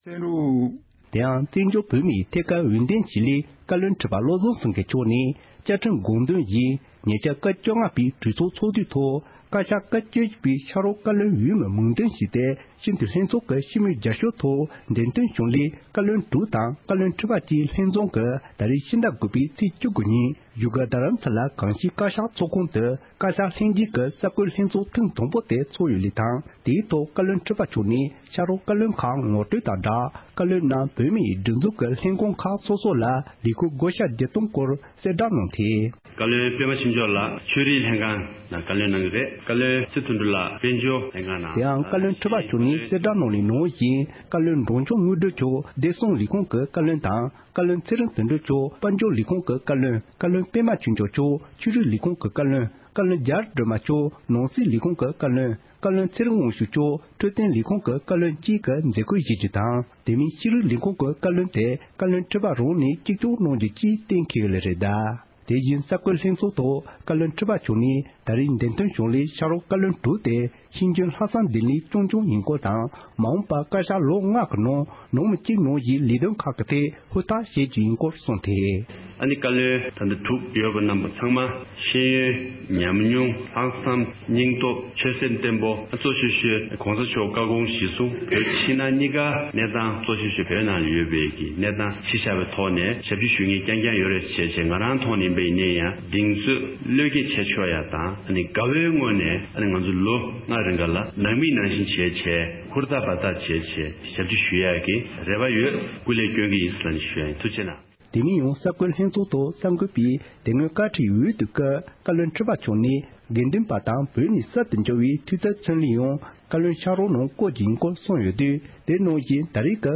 བཀའ་བློན་ཁྲི་པ་མཆོག་ནས་གསར་འགོད་གསལ་བསྒྲགས།
སྒྲ་ལྡན་གསར་འགྱུར། སྒྲ་ཕབ་ལེན།